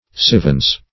Sivvens \Siv"vens\, n. (Med.)